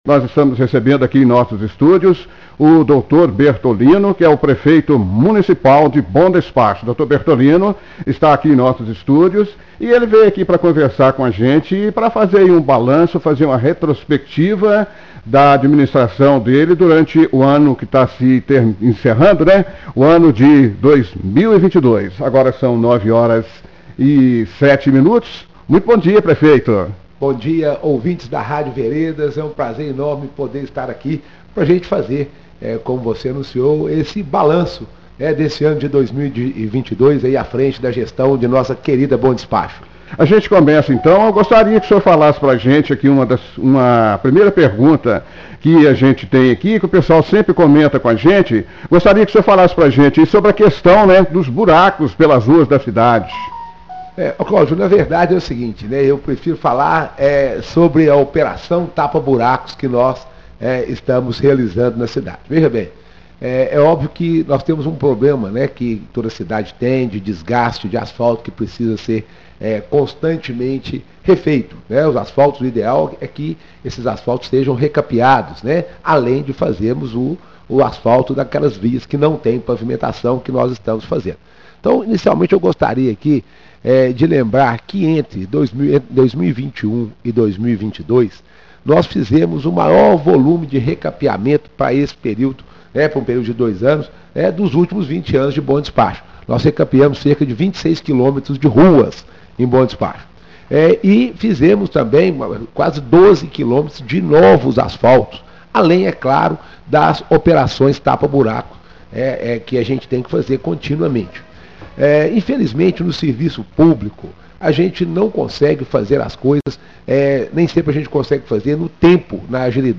Confira agora a entrevista completa do Prefeito Doutor Bertolino, na Rádio Nova Veredas FM.